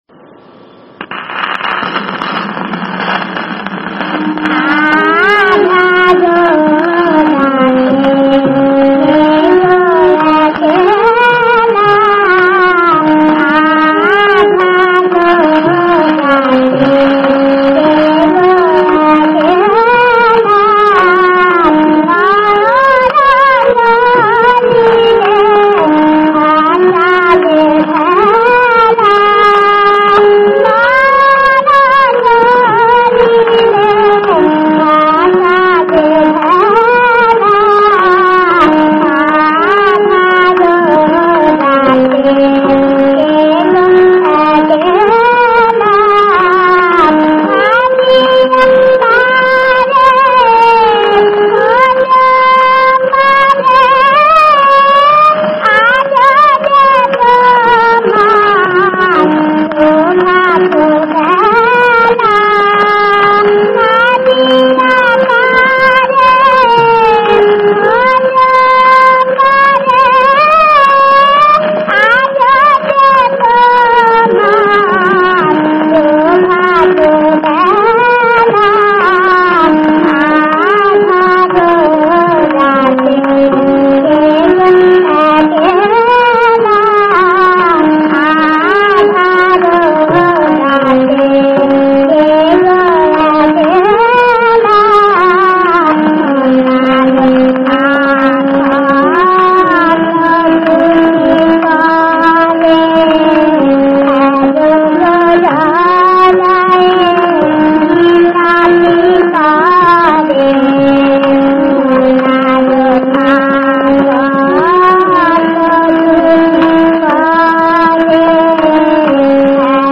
দেশ-পিলু-দাদরা] নজরুল-রচনাবলী, দ্বিতীয় খণ্ড [বাংলা একাডেমী, ফাল্গুন ১৪১৩।
পৃষ্ঠা: ২০-২২ [ নমুনা ] পর্যায়: বিষয়াঙ্গ: প্রেম সুরাঙ্গ: খেয়ালাঙ্গ রাগ: দেশ তাল: ত্রিতাল গ্রহস্বর: র